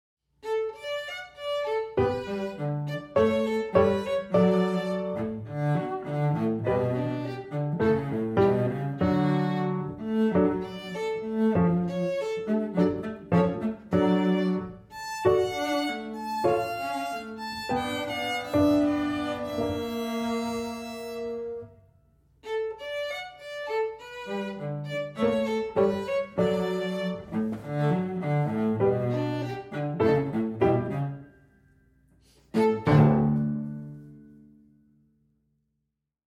In all the pieces, Staff 1 carries the Melody.